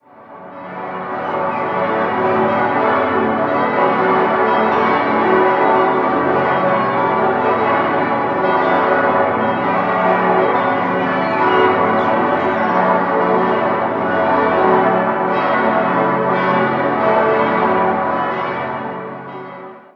Christus und Helena fis°+7 7.970 kg 2.273 mm 1951 Otto, Bremen-Hemelingen Maria a°+2 4.480 kg 1.911 mm 1951 Otto, Bremen-Hemelingen Petrus h°+1 3.500 kg 1.707 mm 1951 Otto, Bremen-Hemelingen